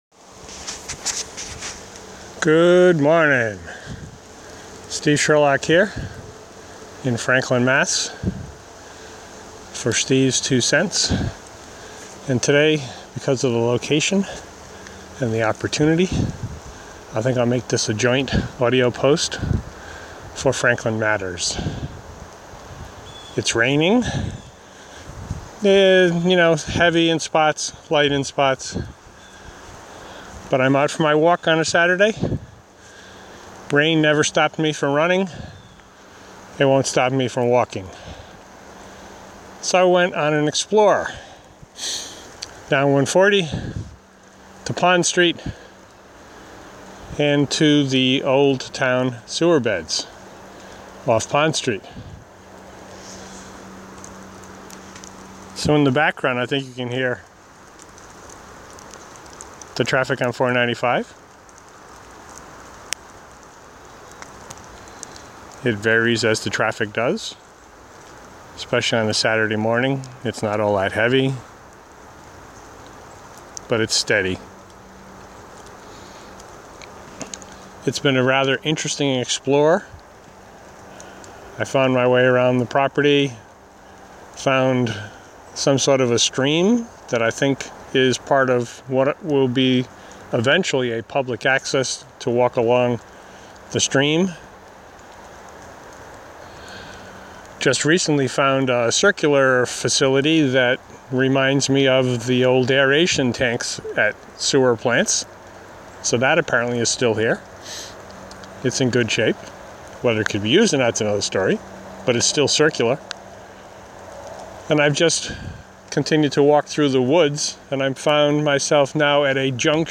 in the rain I went exploring through the land and woods on the former sewer bed property here in Franklin